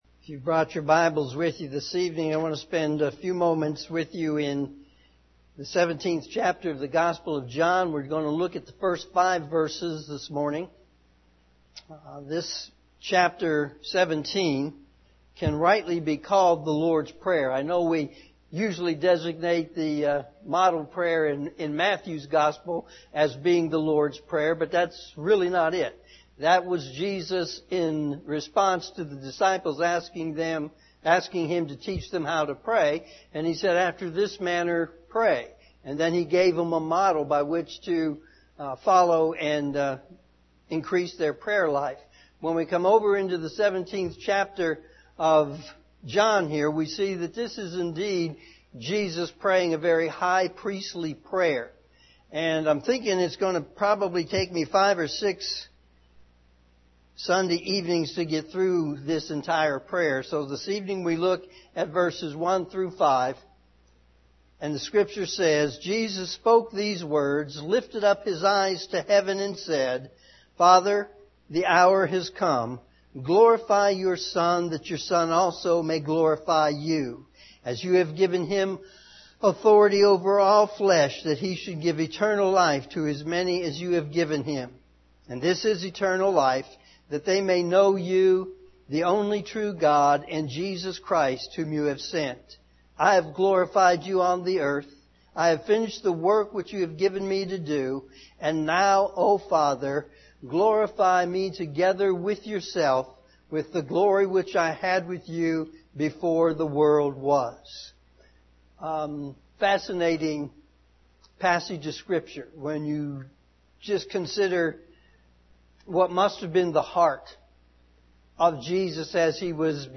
evening service
sermon9-23-18pm.mp3